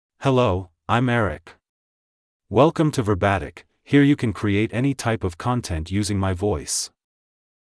MaleEnglish (United States)
EricMale English AI voice
Eric is a male AI voice for English (United States).
Voice sample
Male
Eric delivers clear pronunciation with authentic United States English intonation, making your content sound professionally produced.